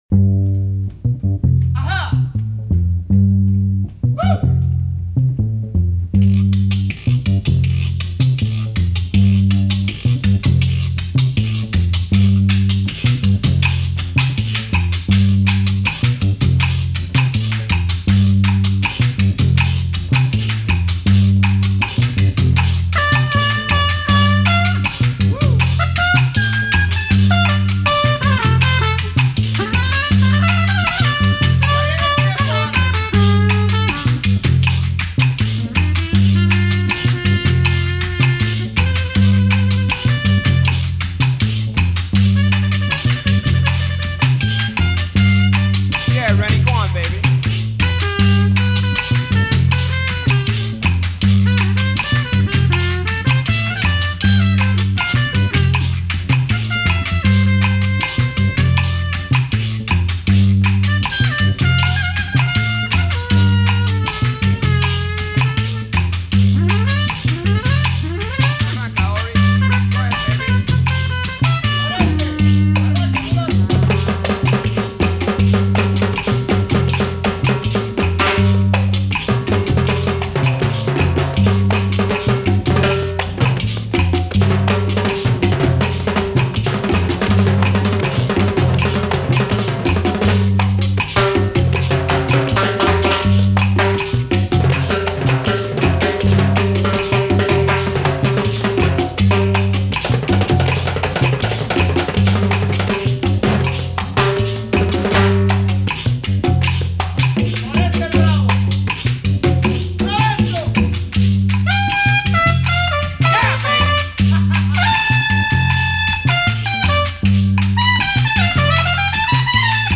Boogaloo par un maître de la percussion latine